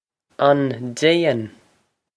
Pronunciation for how to say
On dayn?
This is an approximate phonetic pronunciation of the phrase.